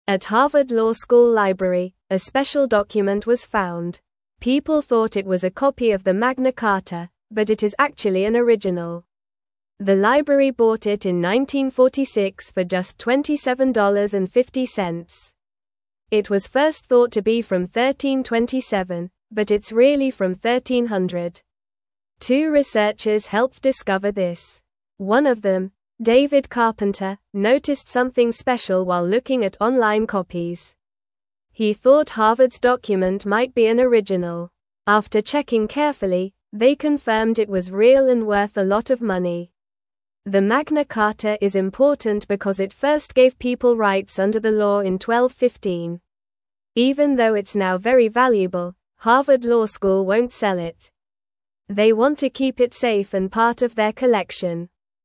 Normal
English-Newsroom-Lower-Intermediate-NORMAL-Reading-Harvard-Finds-Special-Old-Paper-Called-Magna-Carta.mp3